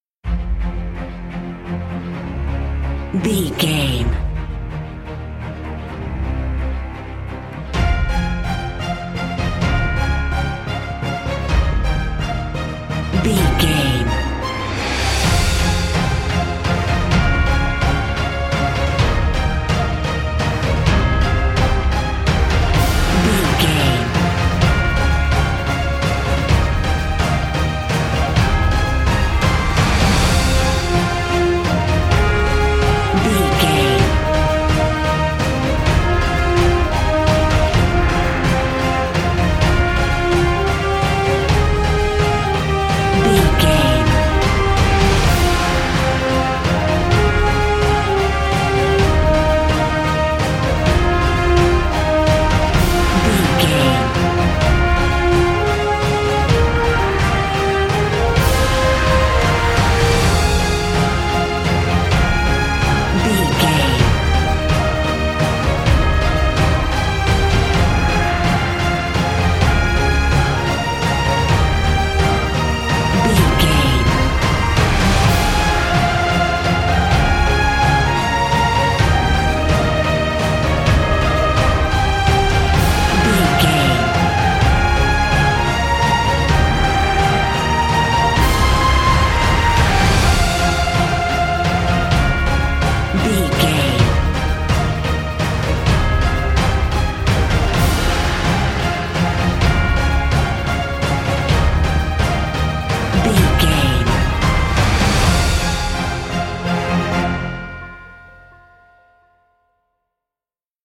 Epic / Action
Fast paced
Uplifting
Aeolian/Minor
Fast
hybrid
brass
orchestra
synthesizers